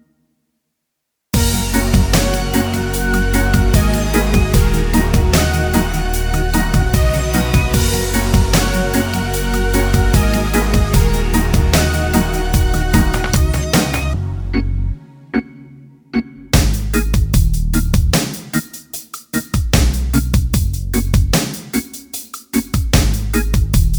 no Backing Vocals R'n'B / Hip Hop 3:32 Buy £1.50